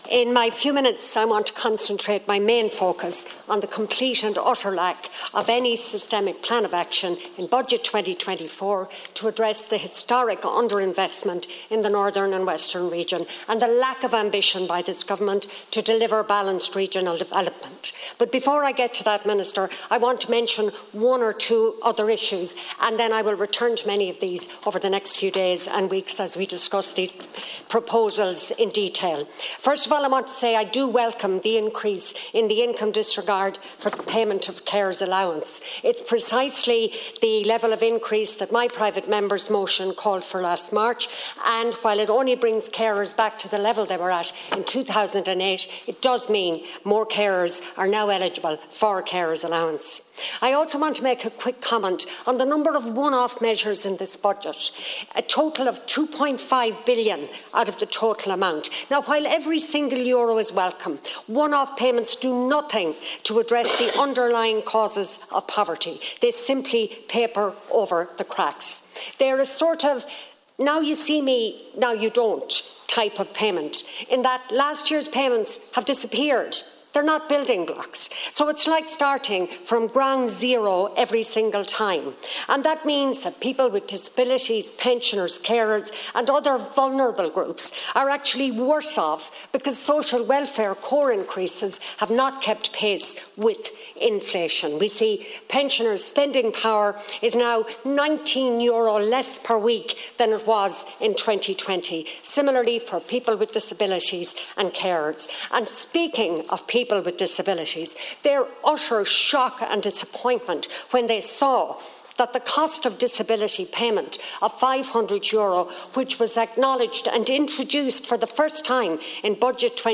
The Dail has been told that the budget has failed to address the imbalance between the West and North West and the rest of the country